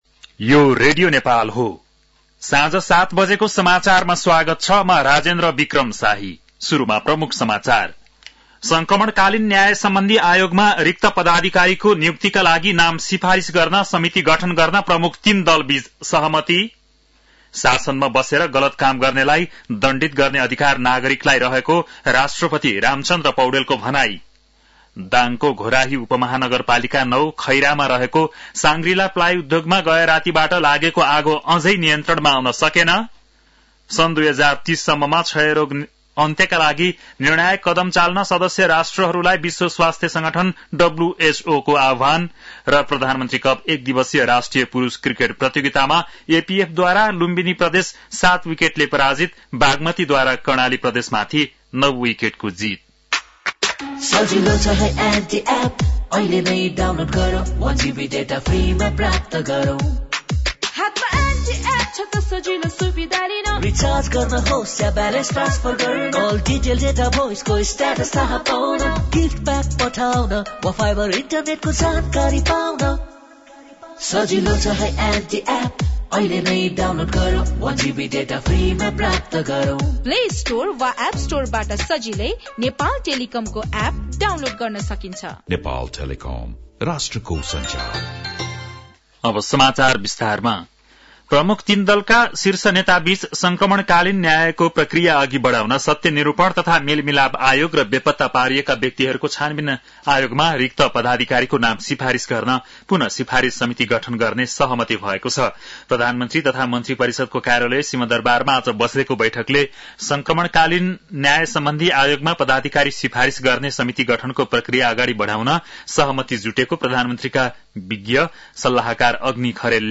बेलुकी ७ बजेको नेपाली समाचार : ११ चैत , २०८१
7-pm-nepali-news.mp3